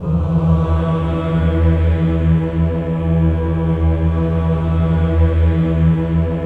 VOWEL MV04-L.wav